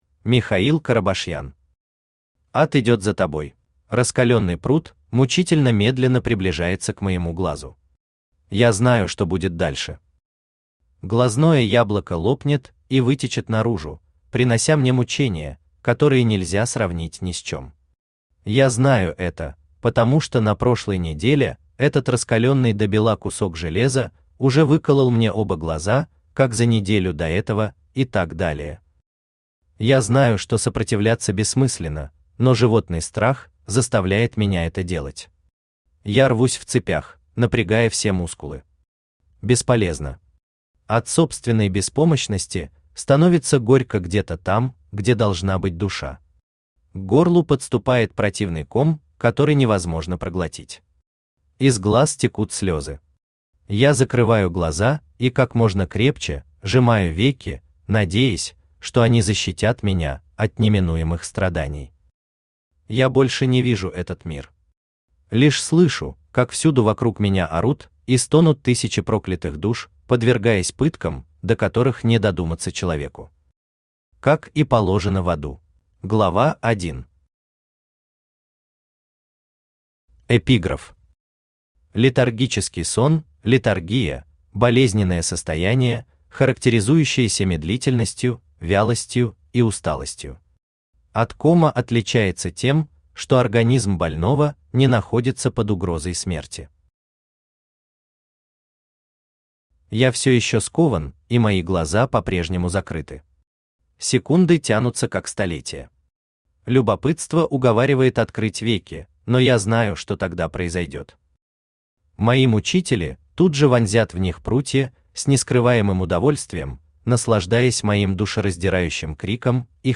Аудиокнига Ад идёт за тобой | Библиотека аудиокниг
Aудиокнига Ад идёт за тобой Автор Михаил Семёнович Карабашьян Читает аудиокнигу Авточтец ЛитРес.